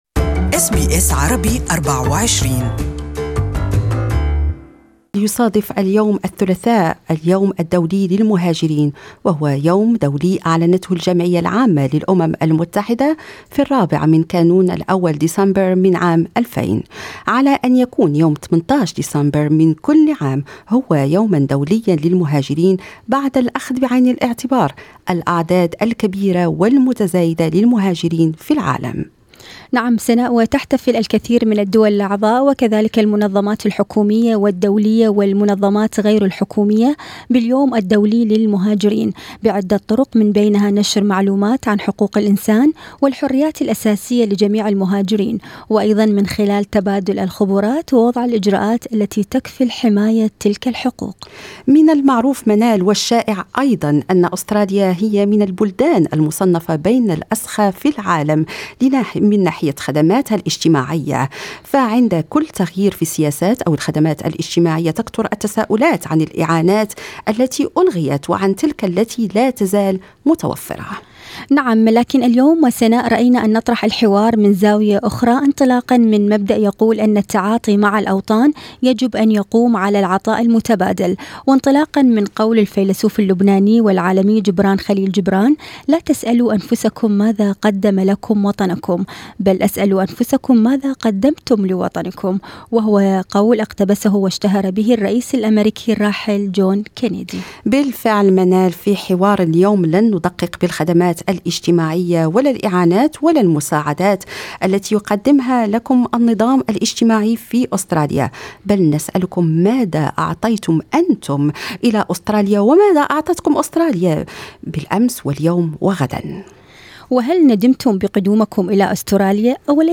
This interview is only available in Arabic